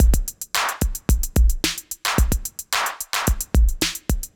Index of /musicradar/80s-heat-samples/110bpm